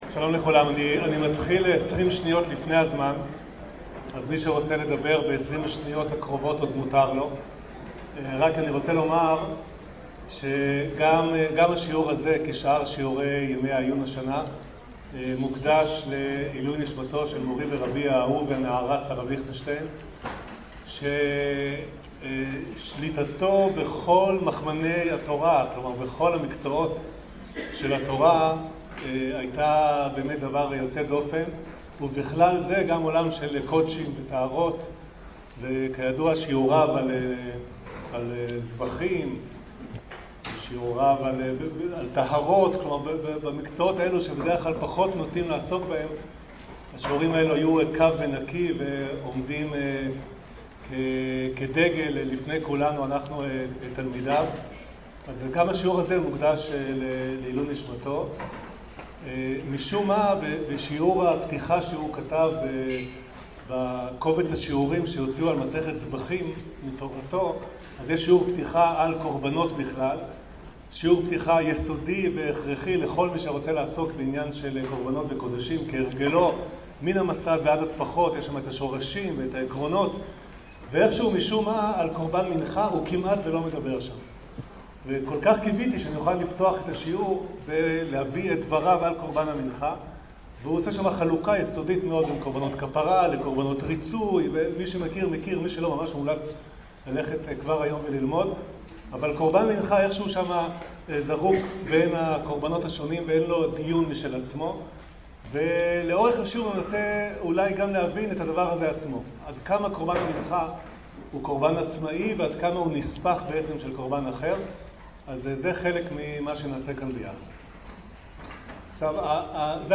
השיעור באדיבות אתר התנ"ך וניתן במסגרת ימי העיון בתנ"ך של המכללה האקדמית הרצוג